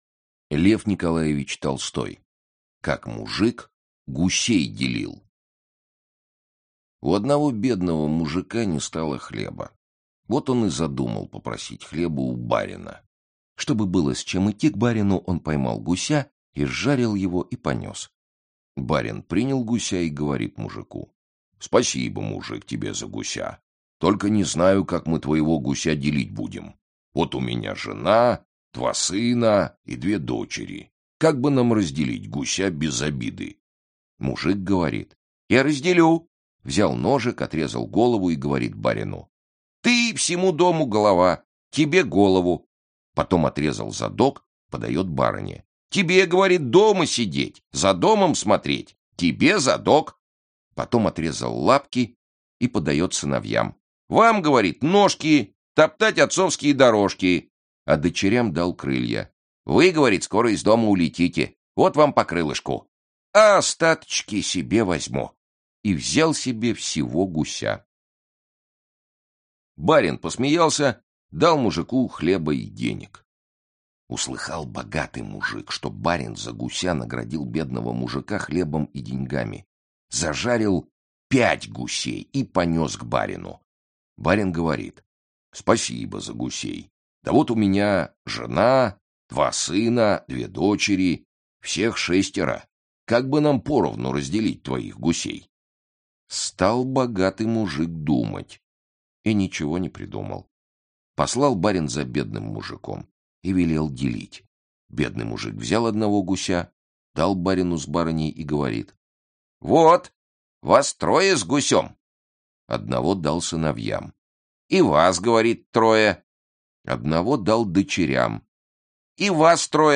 Аудиокнига В гостях у сказки | Библиотека аудиокниг